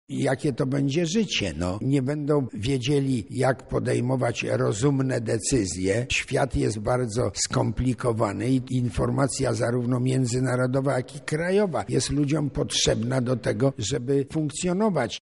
Właśnie o tym mówił Maciej Wierzyński na Wydziale Politologii.
Podczas spotkania ze studentami dziennikarz wyjaśniał, że jest to wynik cięcia kosztów w mediach.